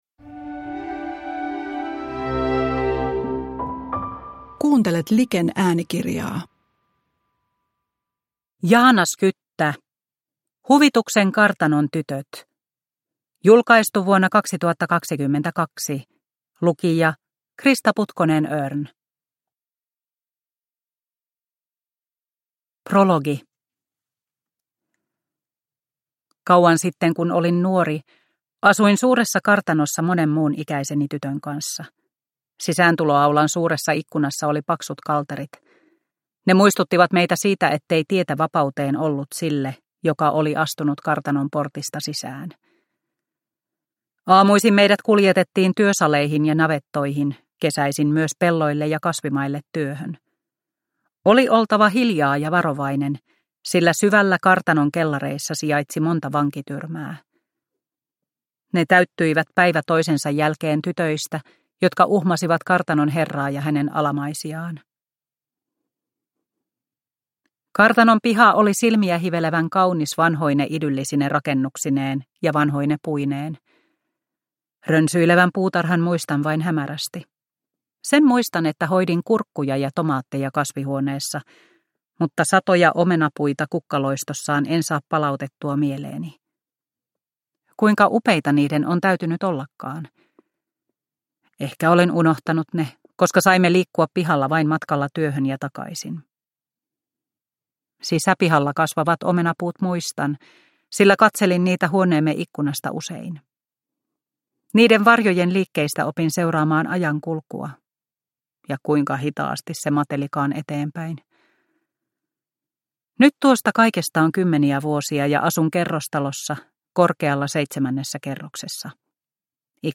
Huvituksen kartanon tytöt – Ljudbok – Laddas ner